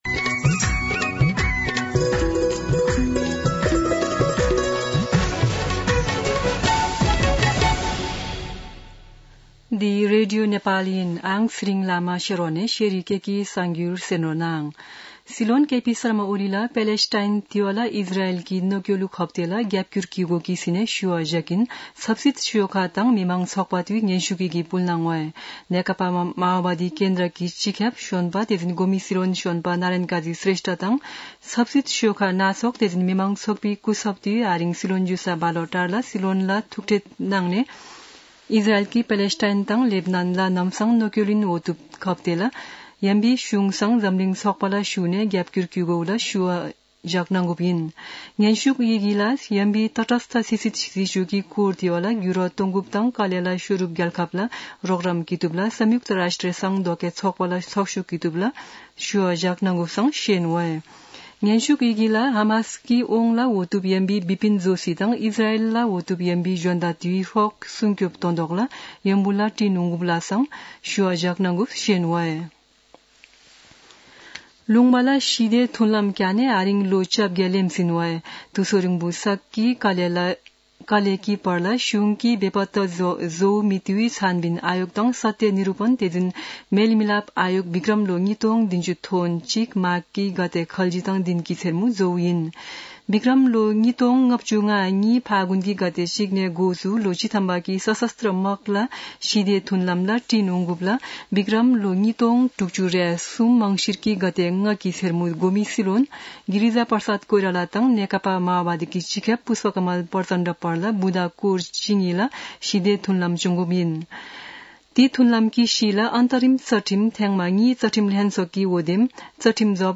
शेर्पा भाषाको समाचार : ६ मंसिर , २०८१
4-pm-Sherpa-news-1-1.mp3